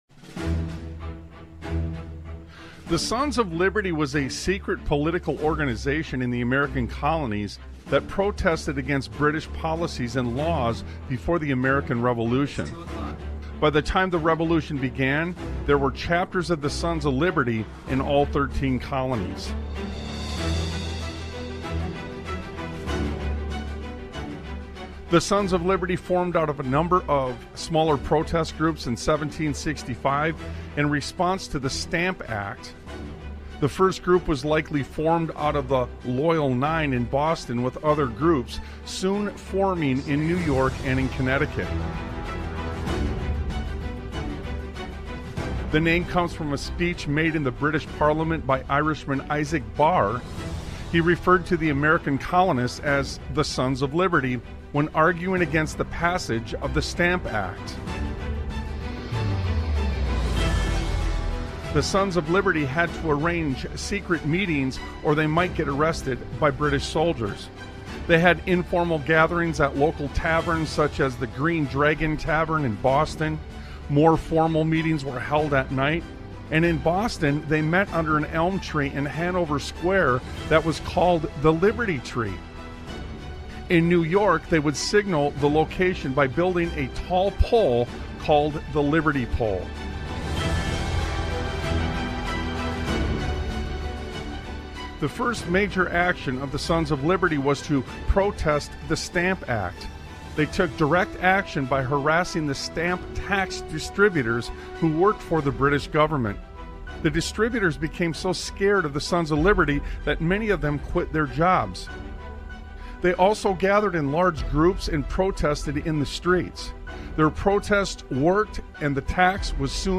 Talk Show Episode, Audio Podcast, Sons of Liberty Radio and Laying The Foundation, Brick By Brick on , show guests , about Laying The Foundation,Brick By Brick,Reclaiming the Foundation of Liberty and Faith,historical providence,the restoration of Biblical governance,Confronting Corruption and Global Conflict,The Modern Crisis,Apathy and Revisionism,The Cost of Independence,Divine Providence and the Founding Fathers, categorized as Education,History,Military,News,Politics & Government,Religion,Christianity,Society and Culture,Theory & Conspiracy